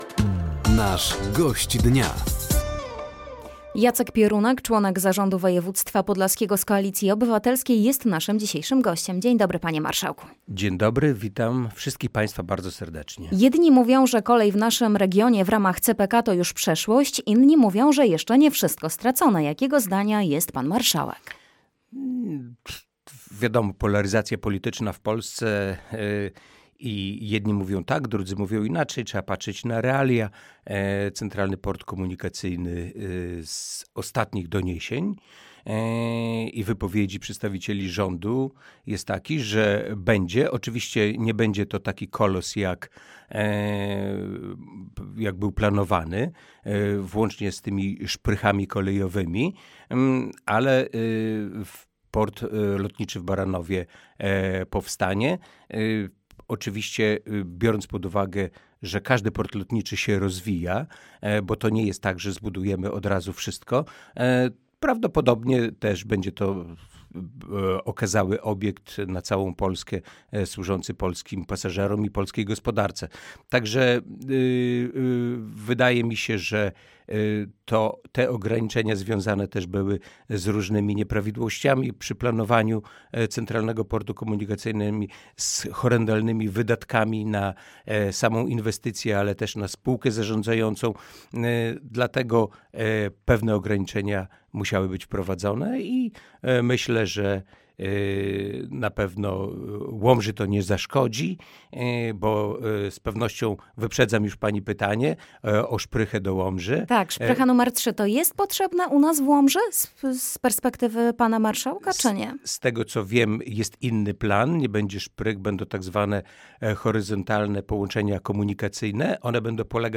Gościem Dnia Radia Nadzieja był Jacek Piorunek, członek zarządu województwa podlaskiego. Tematem rozmowy była szansa na kolej w regionie w ramach CPK, inwestycje wspierające młodych mieszkańców województwa, a także kierunek rozwoju Podlasia.